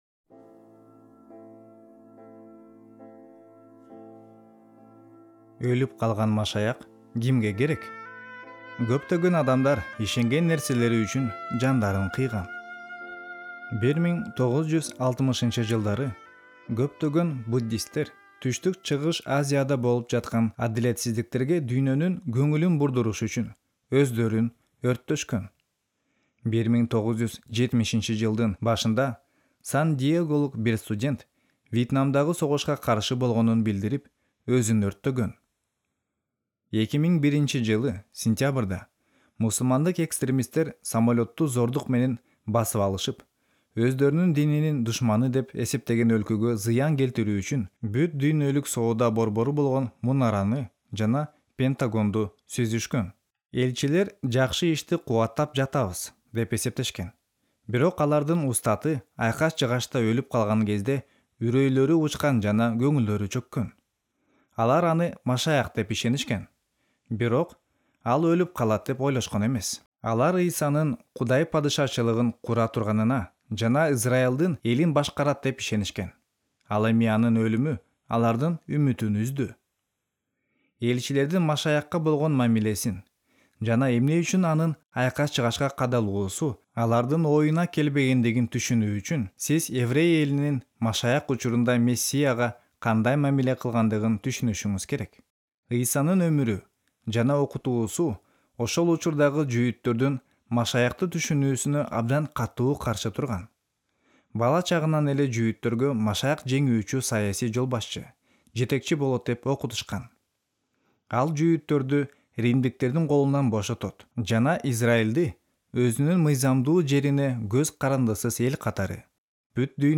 Аудио-китеп угуу